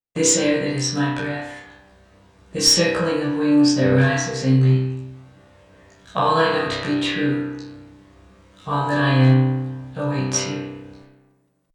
Processing: stereo + KS delays 457/305 (C/G); feedback 920/940-> 950/970